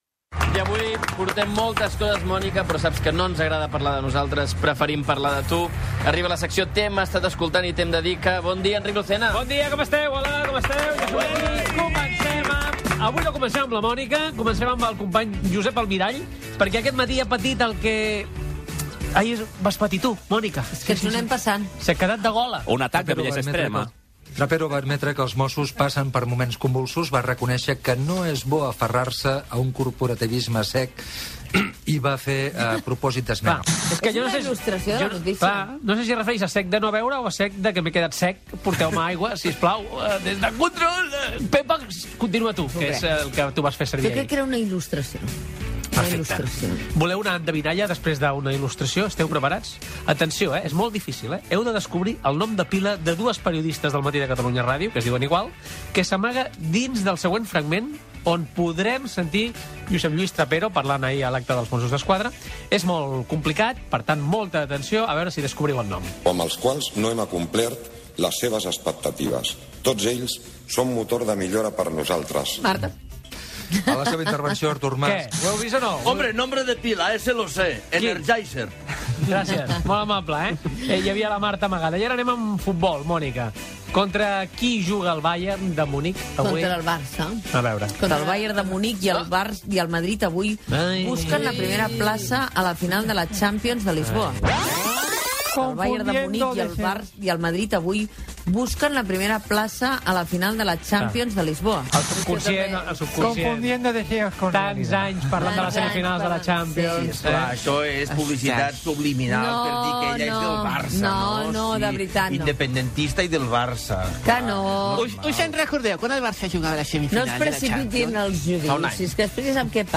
Espai humorístic "Els minoristes" amb imprevistos, curiositats i equivocacions radiofòniques i una endevinalla
Info-entreteniment